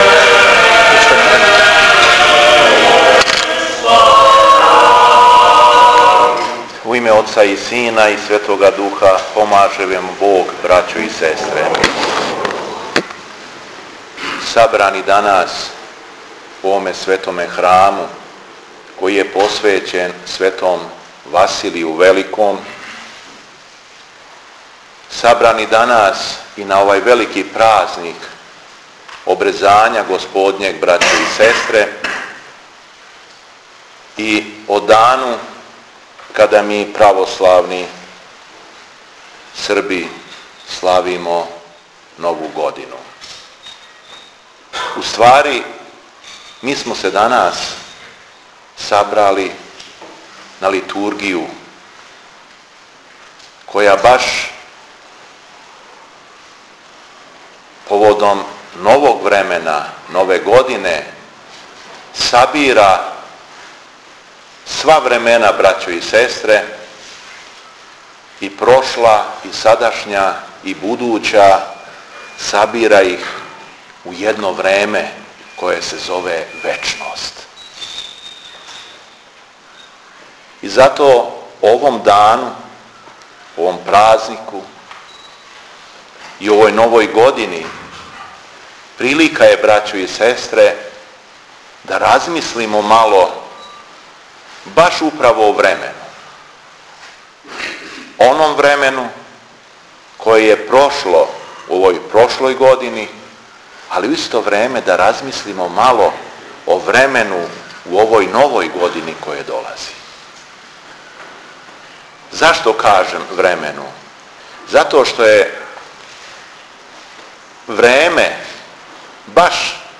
Беседа епископа шумадијског Г. Јована у Маршићу